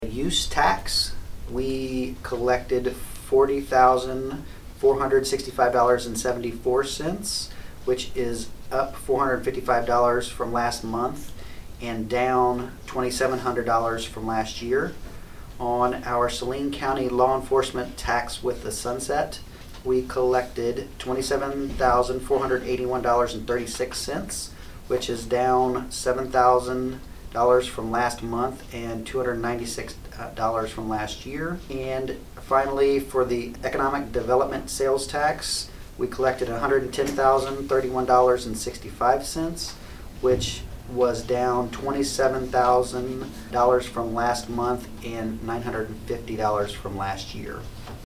Saline County Treasurer Jared Brewer gave his monthly report on the sales-tax money the county received this month at the meeting of the county commission on Thursday, July 8.